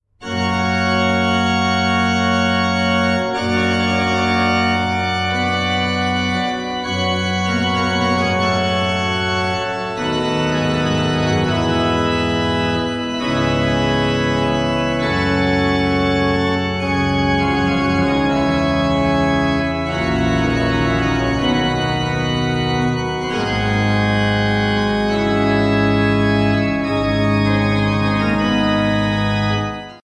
Orgel und Cembalo
Seine Hauptgattung war die Toccata.